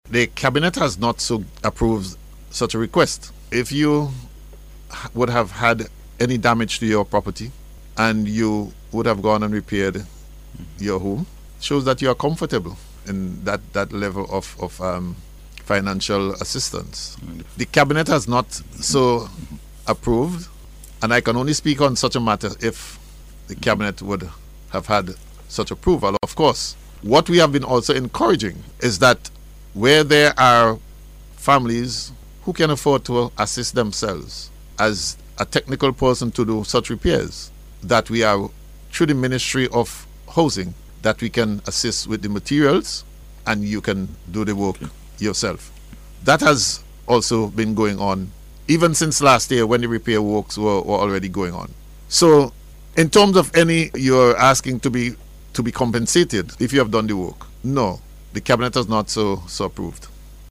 He made the announcement, during NBC’s Face to Face programme, as he provided an update on the progress being made in relation to assisting persons whose homes were damaged or destroyed by the volcanic eruption.